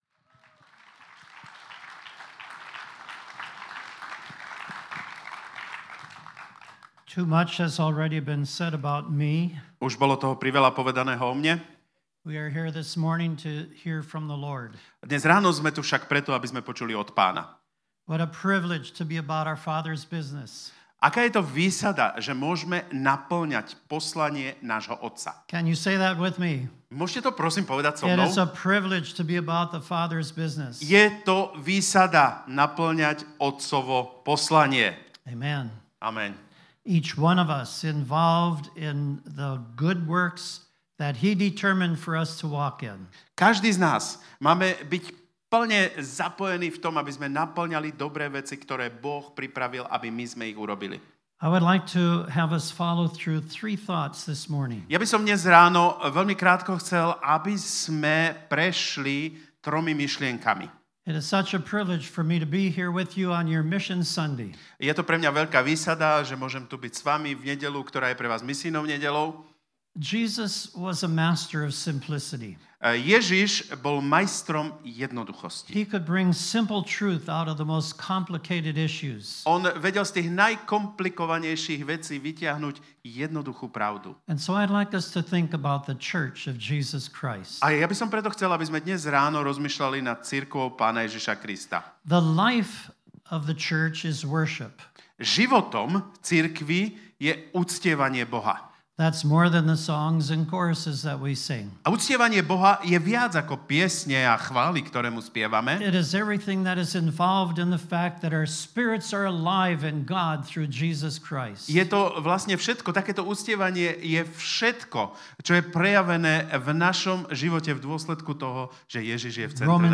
Misijná nedeľa